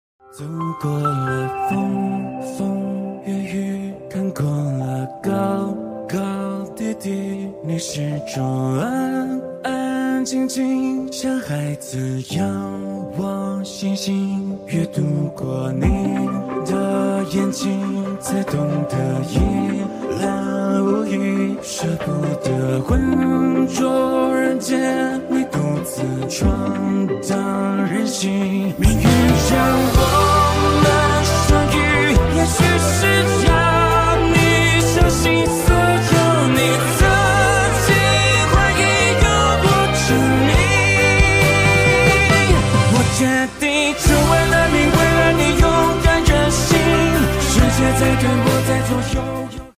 Single Shaft Shredder Machine for sound effects free download
Single Shaft Shredder Machine for HDPE PE PP Pipe Lumps Recycling Waste Plastic Bottles Shredder Raw material: HDPE lumps